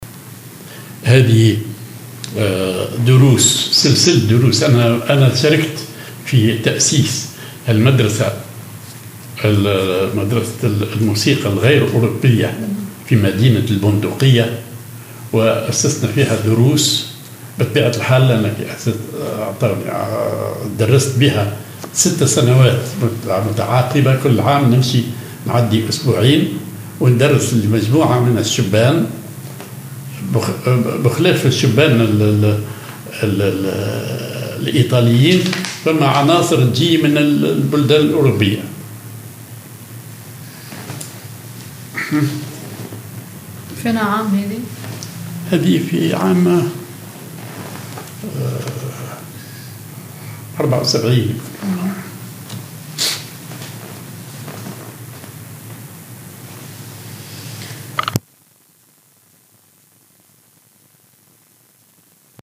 سلسلة دروس في المدرسة الغير أوروبية بالبندقية
سلسلة دروس للأستاذ صالح المهدي في البندقية